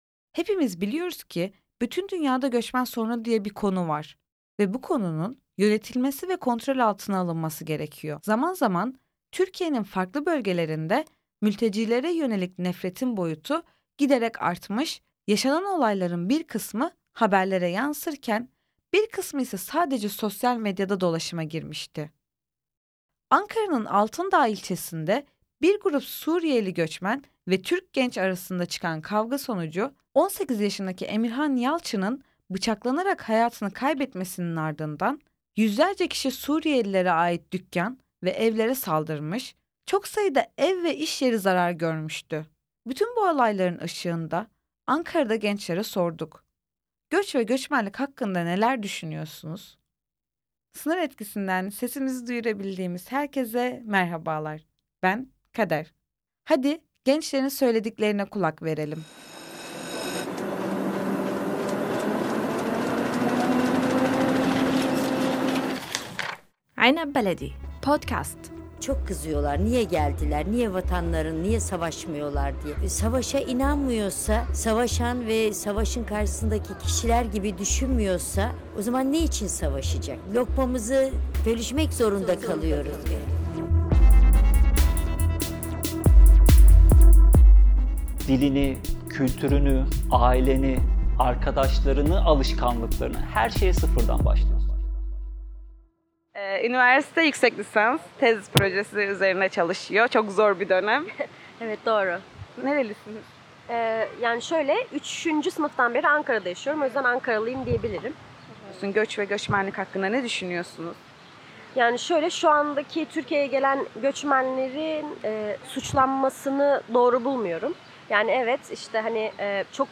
Göç ve Göçmen - Röportaj | Sınır Etkisi